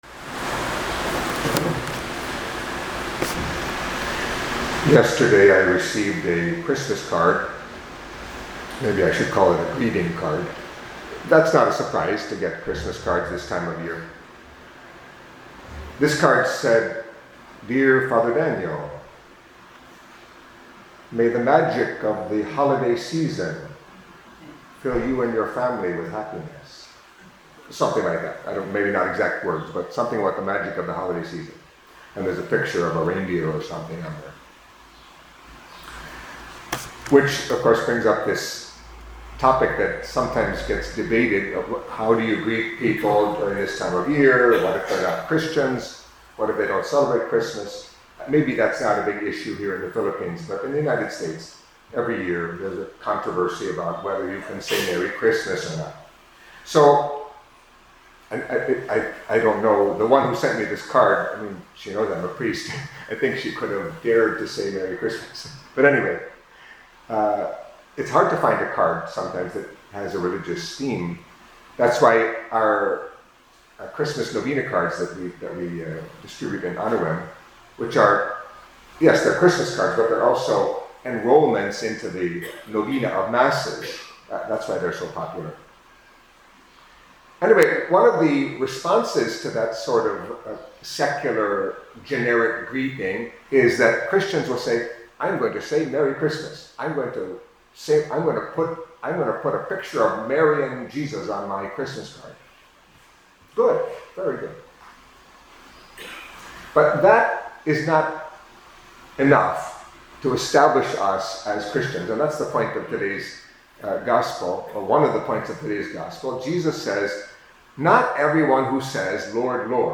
Catholic Mass homily for Thursday of the First Week of Advent